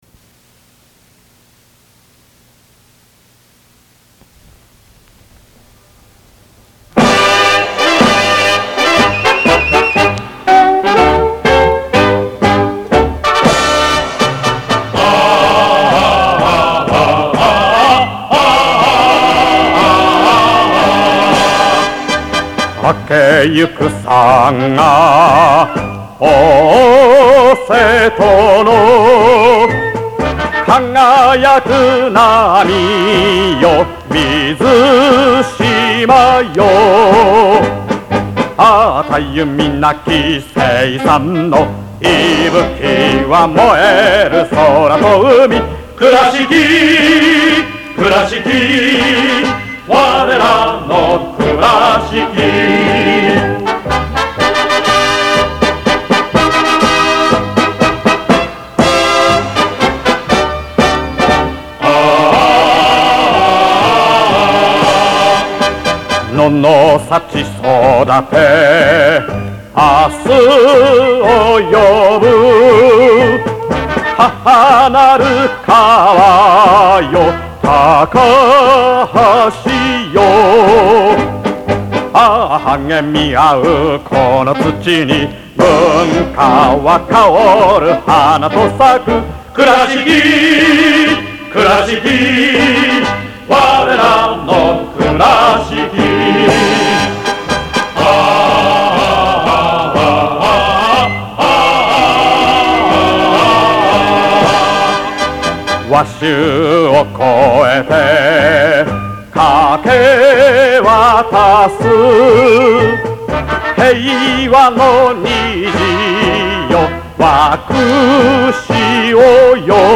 kurashikishika_vocals.mp3